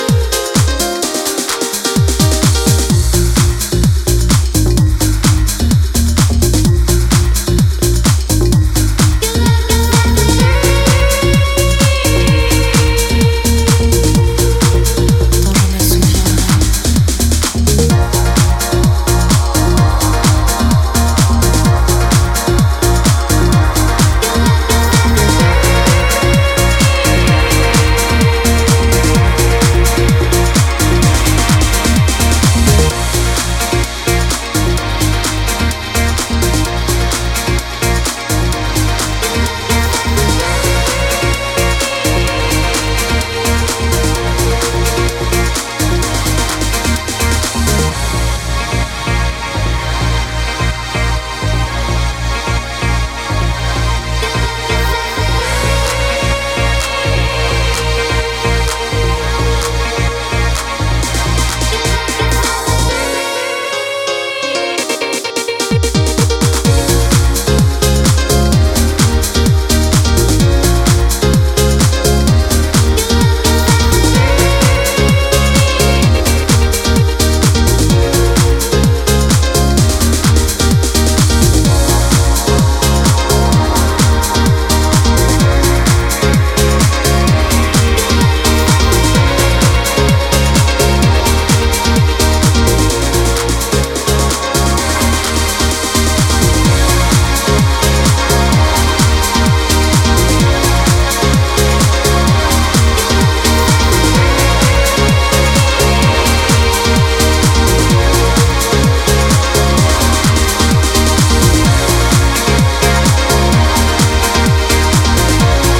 two unashamedly old-school Scottish club cuts